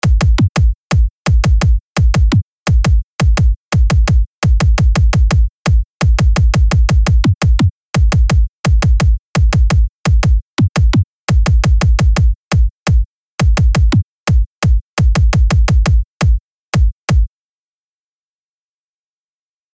But in “boredom” not really boredom, more frustration, I decided to punch in a “kick” beat to the spacing of ribosomes along the RER membrane.